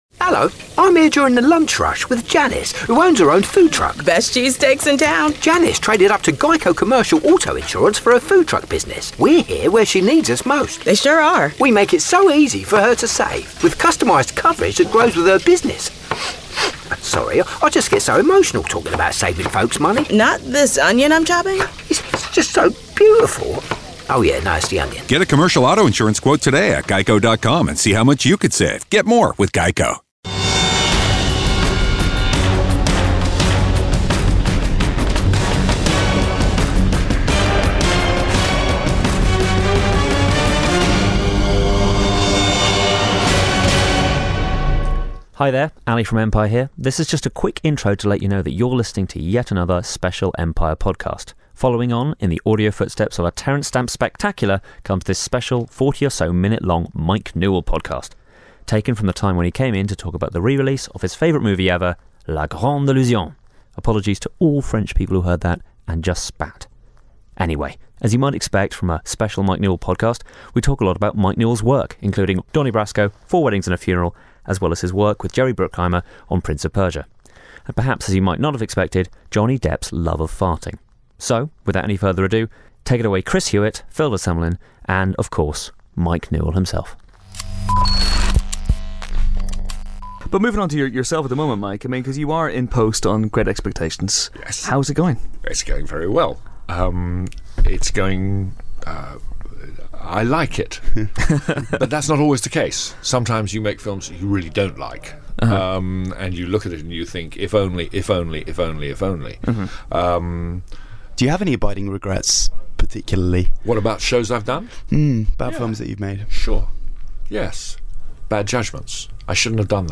Mike Newell is the man behind Four Weddings And A Funeral, Donnie Brasco, Harry Potter And The Goblet of Fire and many more - and recently, he came into the Empire office to talk about his favourite film ever, La Grande Illusion. But after that, he spent some time with us chatting about the rest of his career... and Johnny Depp's unusual activities on set.